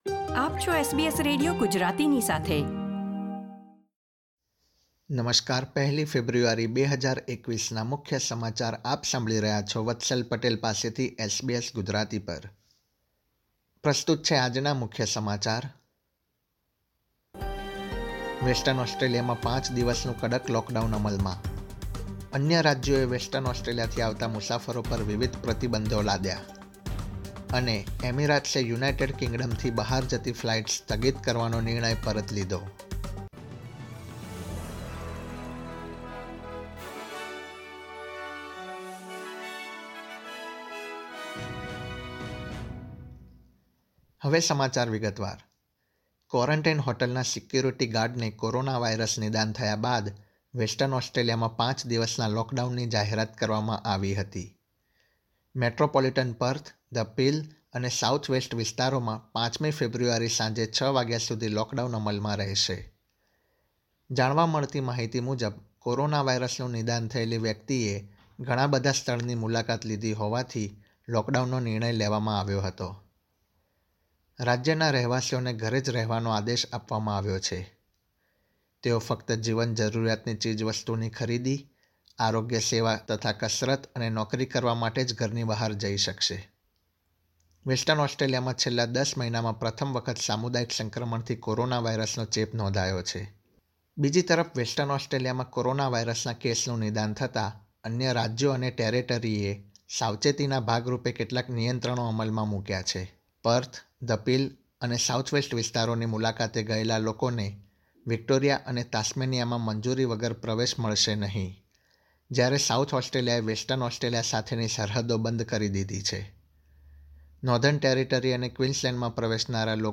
SBS Gujarati News Bulletin 1 February 2021
gujarati_0102_newsbulletin.mp3